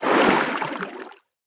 Sfx_creature_snowstalker_swim_04.ogg